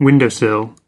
Transcription and pronunciation of the word "windowsill" in British and American variants.